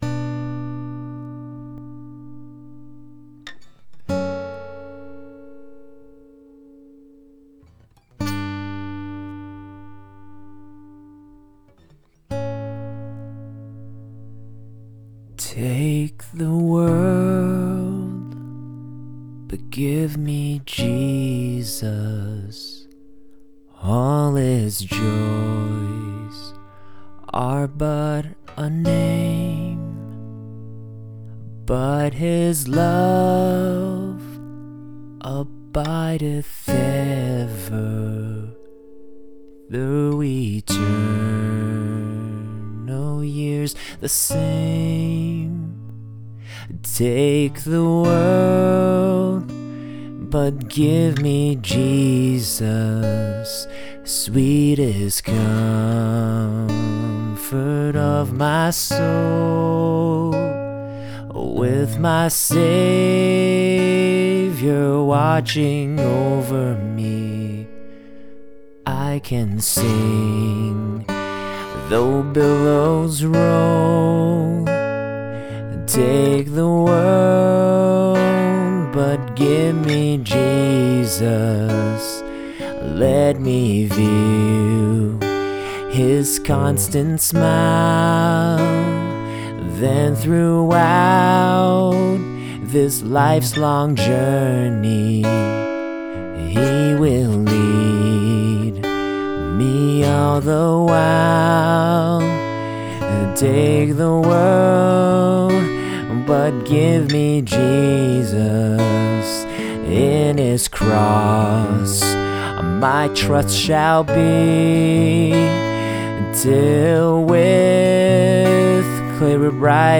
[COVER]